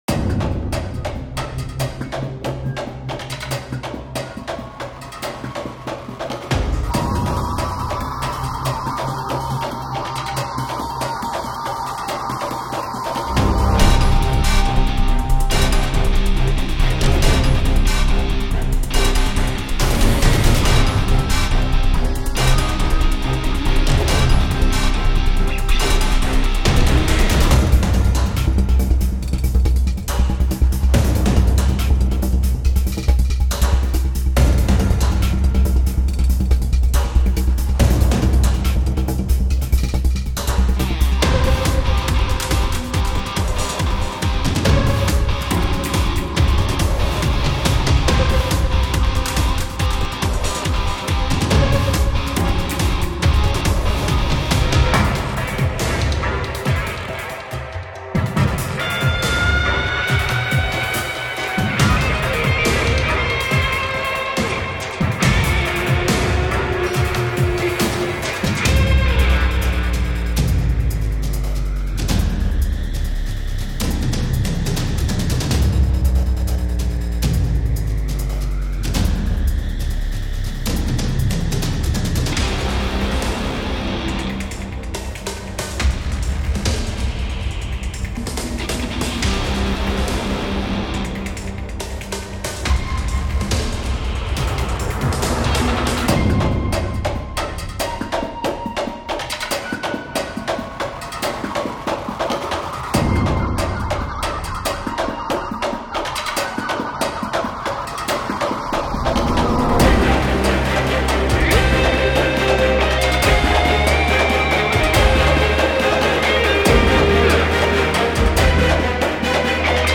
Sci Fi / Action